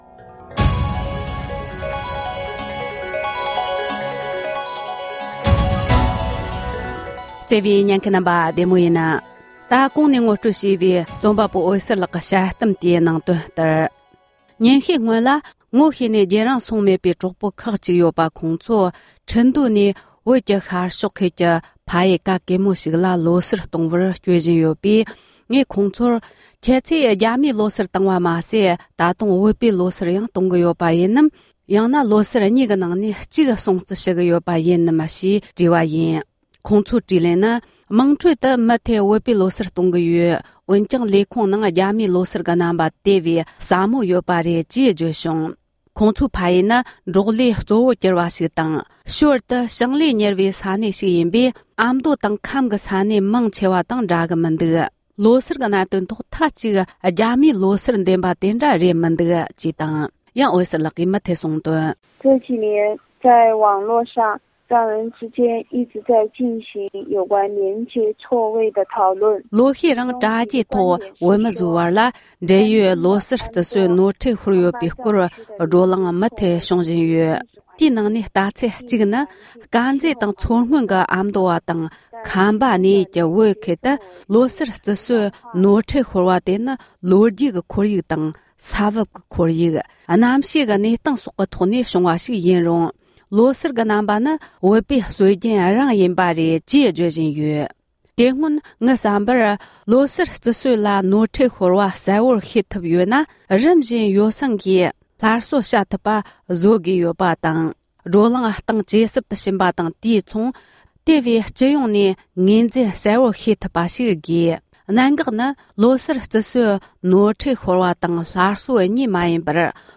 བུད་མེད་རྩོམ་པ་པོ་གྲགས་ཅན་འོད་ཟེར་ལགས་ཀྱིས་སྤེལ་བའི་དཔྱད་གཏམ།